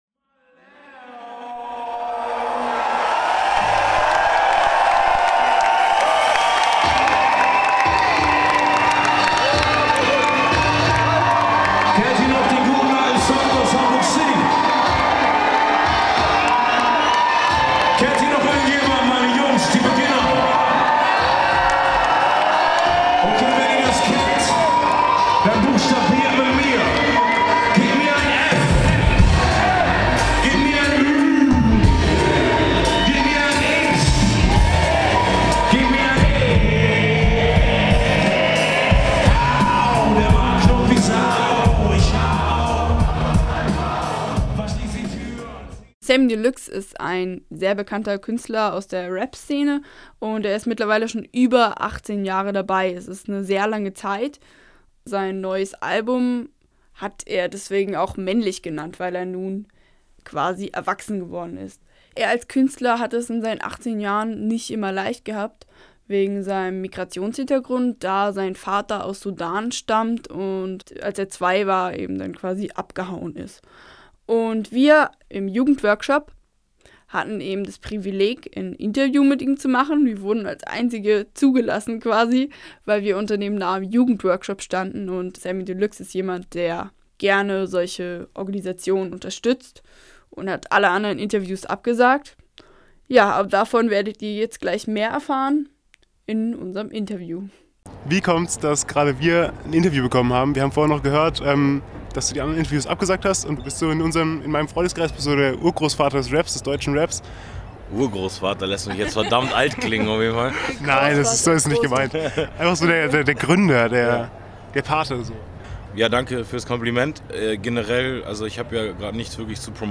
ZMF 2014 Interview mit Sammy Deluxe
Ein Interview mit Sammy Deluxe mal von einer anderen Seite.